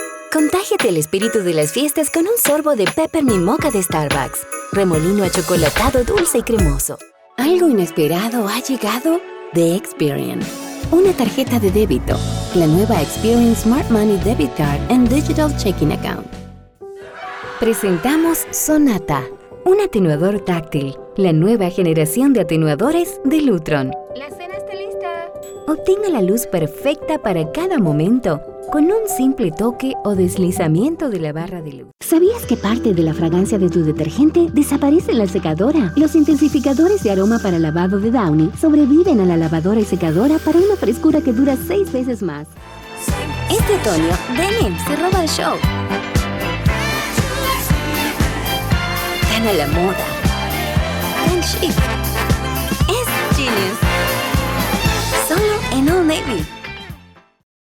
Professional Female Voice Spanish English
Web Commercial - Neutral LatAm Spanish
TV commercial - Spanish US